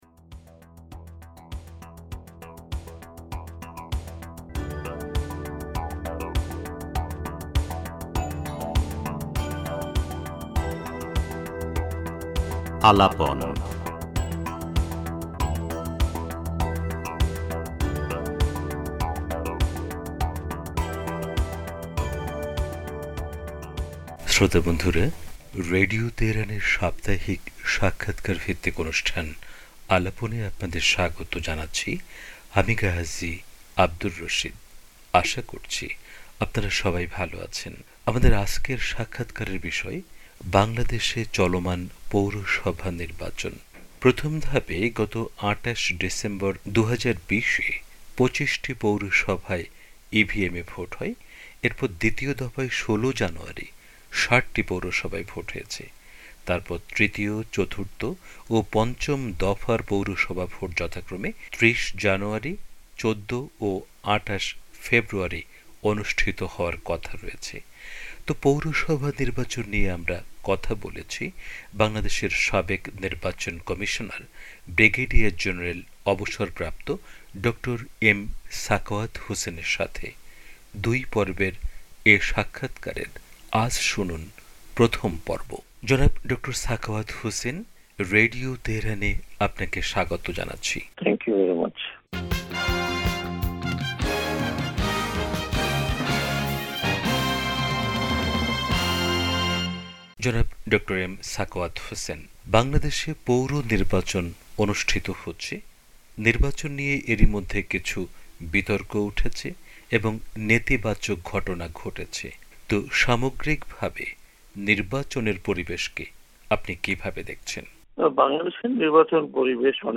সাক্ষাৎকারের প্রথম পর্ব তুলে ধরা হলো।